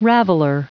Prononciation du mot raveler en anglais (fichier audio)
Prononciation du mot : raveler